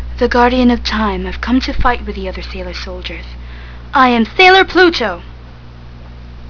Sense we haven't started the dub yet I put up audition clips.